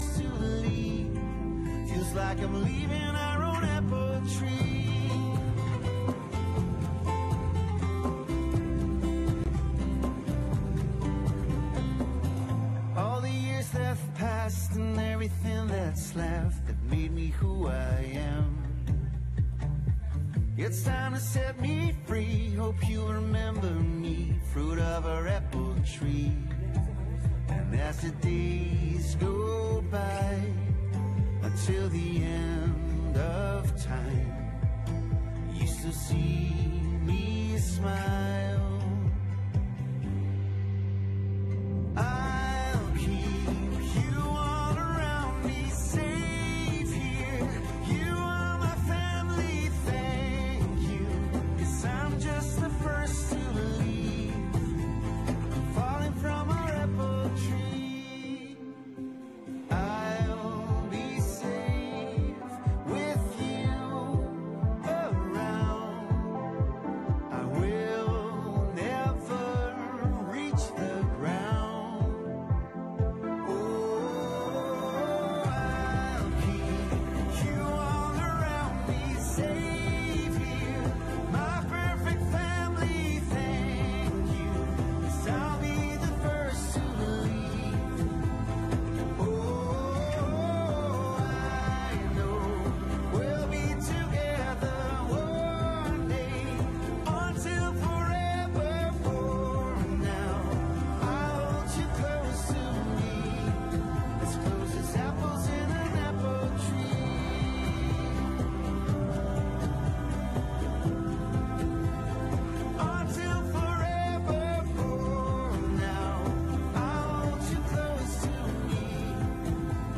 Locatie: Theaterzaal/Rabobankzaal
Toespraak door commissaris van de Koning, de heer Daniël Wigboldus